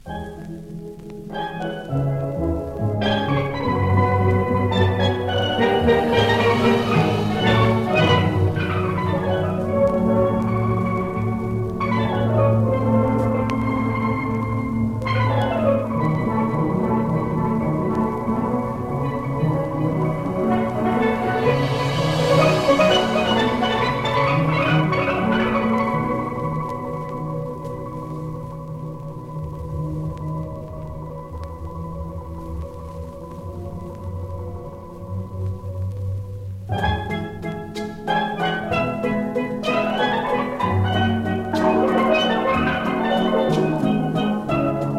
World, Steel band　Trinidad & Tobago　12inchレコード　33rpm　Stereo